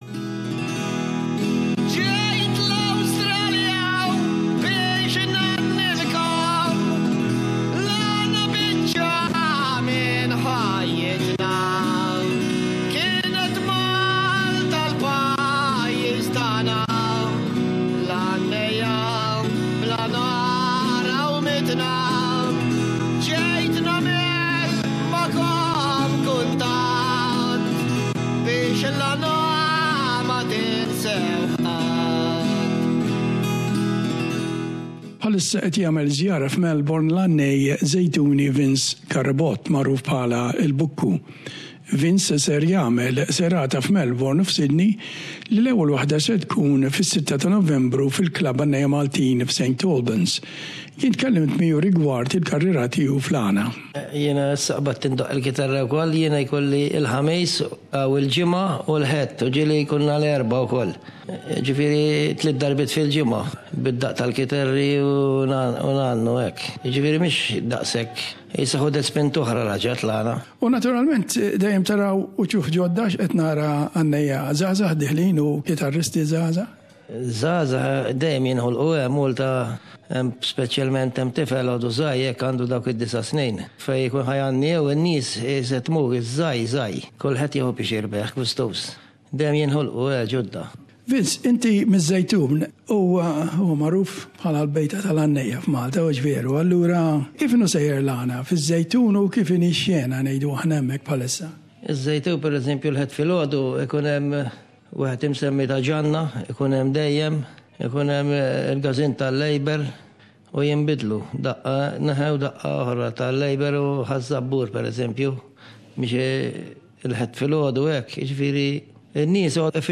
Maltese folk singer